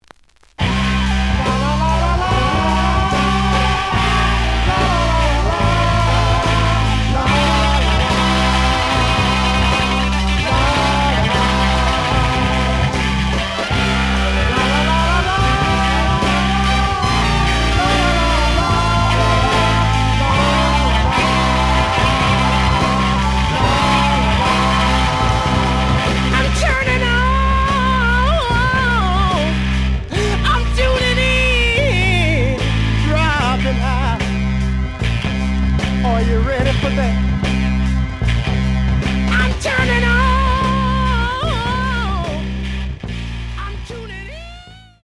The audio sample is recorded from the actual item.
●Genre: Funk, 60's Funk
Slight edge warp.